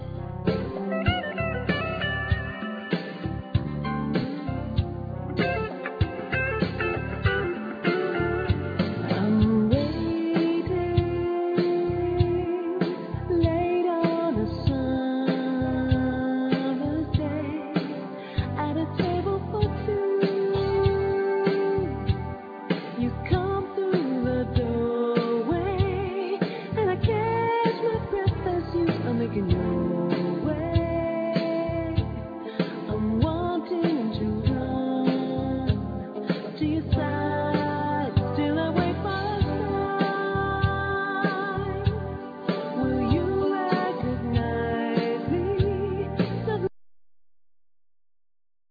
Guitar,Keyboards
Drums
Keyboards,Piano,Voice
Percussion
Double Bass
Vocal